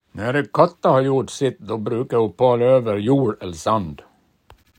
Så här kan palâ användas i en mening